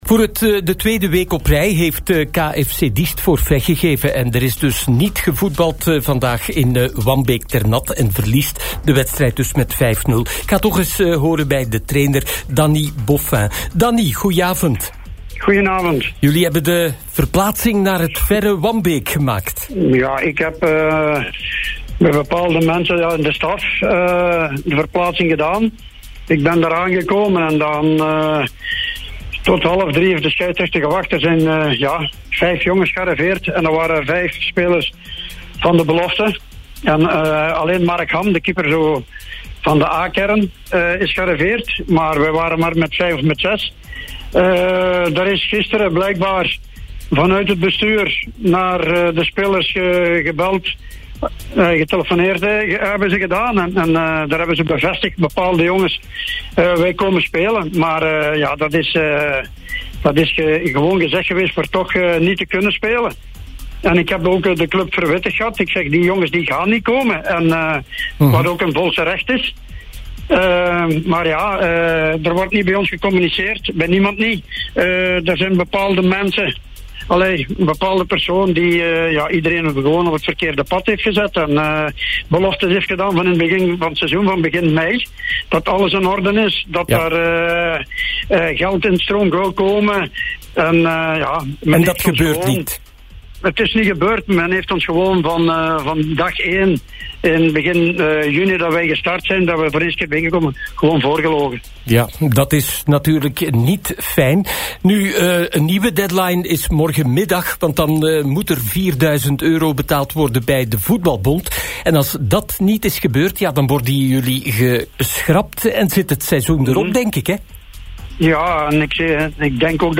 Beluister hier het interview met Danny Boffin.